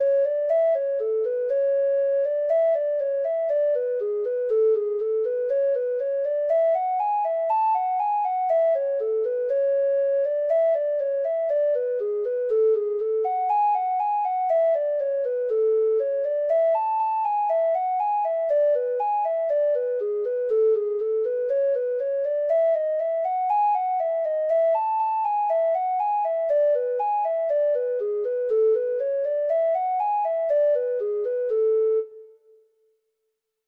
Traditional Trad. Robin Redbreast (Irish Folk Song) (Ireland) Treble Clef Instrument version
Traditional Music of unknown author.
Reels
Irish